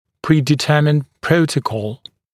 [ˌpriːdɪ’tɜːmɪnd ‘prəutəkɔl][ˌпри:ди’тё:минд ‘проутэкол]заранее определенный протокол